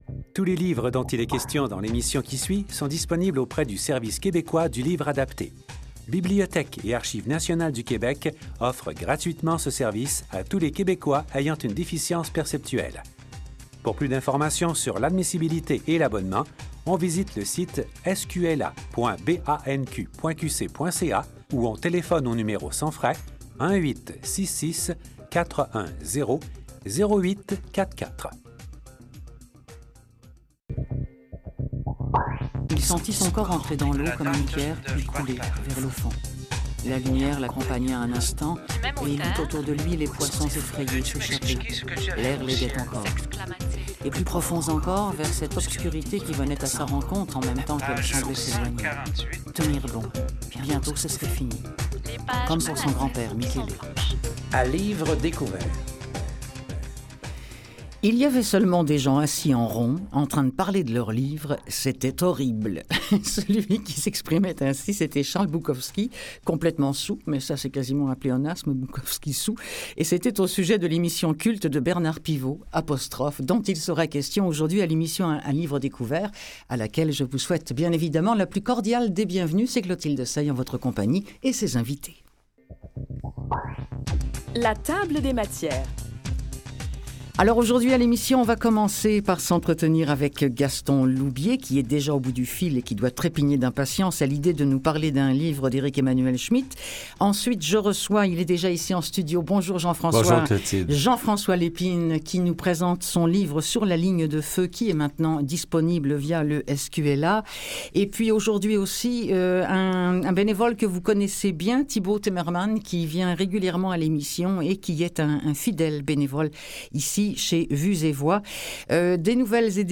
Celui qui a publié Sur la ligne de feu en 2014 est en studio pour discuter, notamment, de ses oeuvres, mais aussi du métier de journaliste et de l'ouverture médiatique du Québec sur le monde et…